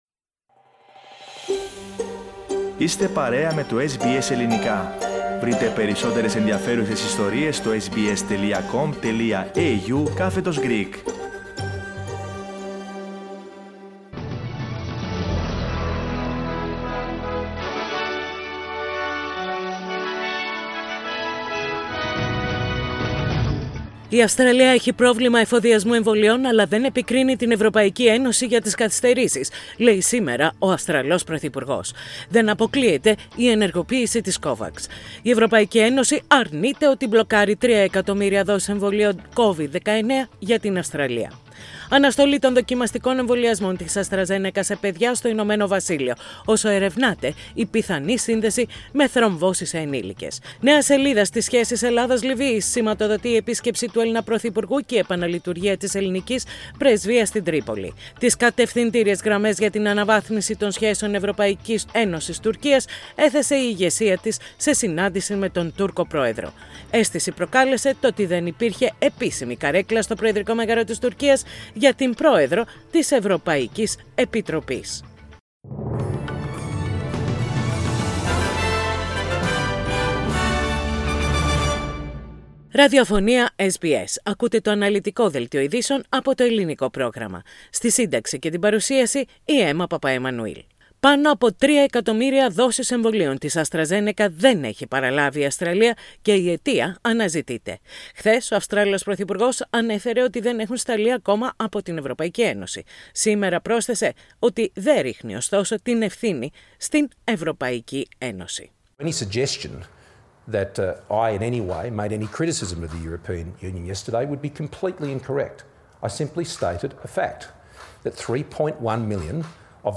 Ειδήσεις στα Ελληνικά - Τετάρτη 7.4.21
Πατήστε Play στην κεντρική φωτογραφία για να ακούσετε το Δελτίο Ειδήσεων της ημέρας.